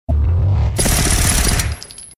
RAPID GUNSHOTS.mp3
Original creative-commons licensed sounds for DJ's and music producers, recorded with high quality studio microphones.
rapid_gunshots_ybs.ogg